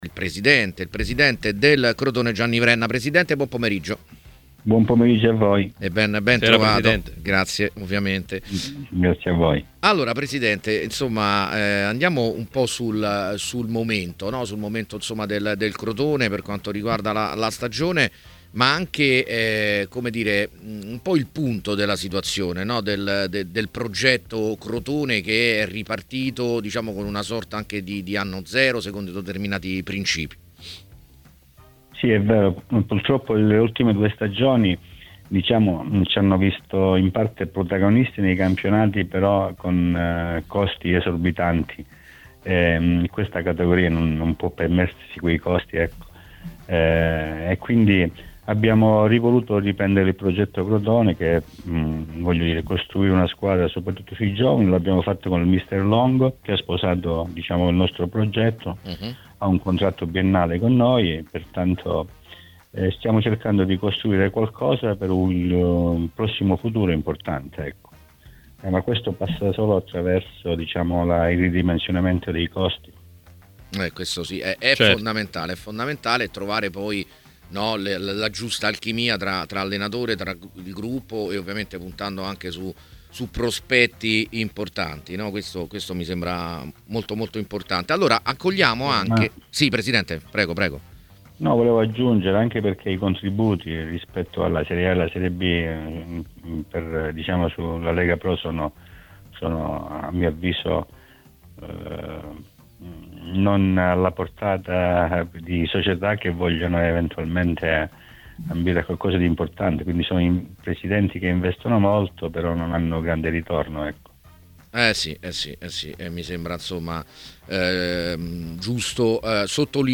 Durante l'appuntamento odierno con A Tutta C sulle frequenze di TMW Radio